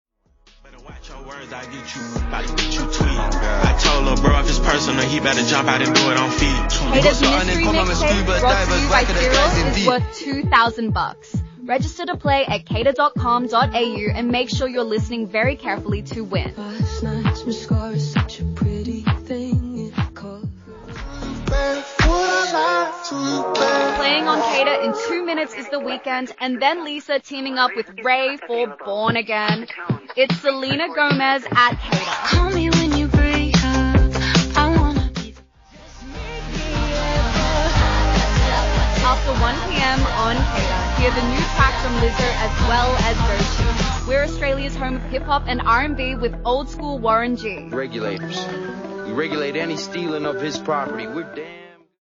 Someone types a few things for her to say, and “she” says it.
There’s no reason to keep listening, no feeling of community, just automated music back to back.
You’ll not be able to hear much of what she says, since her voice is too quiet in the mix, and it’s obvious that nobody at ARN has even bothered to listen to it before it aired.
This website says that ARN has a deal with ElevenLabs, which is a voice synthesis tool.
Of what you can hear in the badly-mixed output, it’s a bad, bad clone.